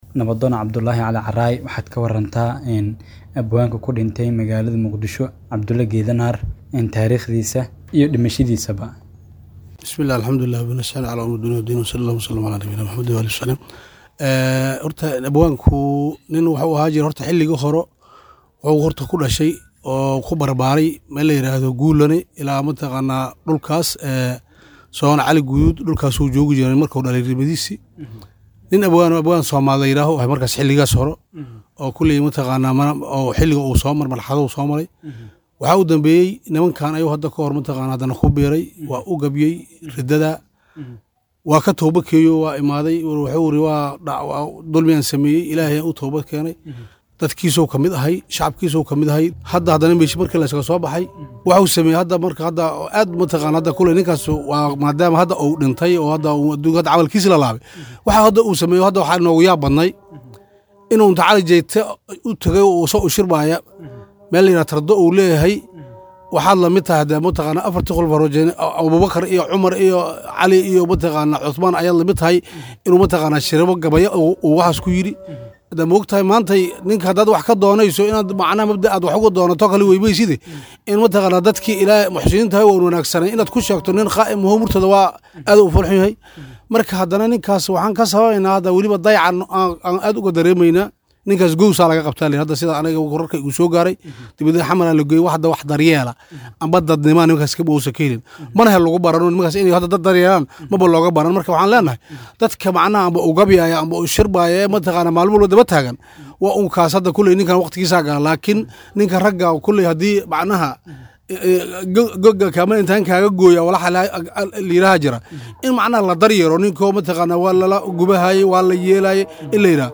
Abwaan la Dhacsanaa Duullaanka Shisheeye oo ku Geeriyooday Muqdisho.[WAREYSI]